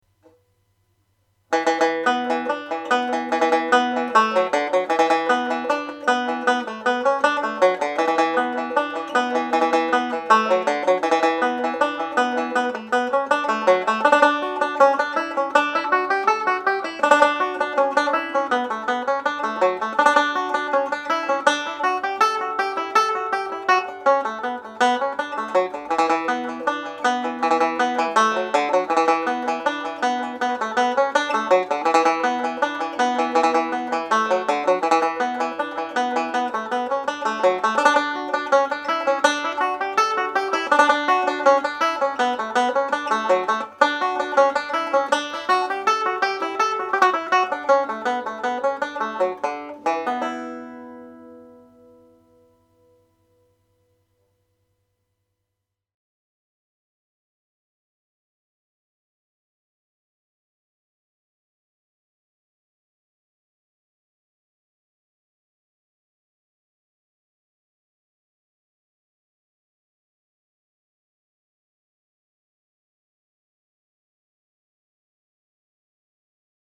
Reel (E Minor)
Played at reel speed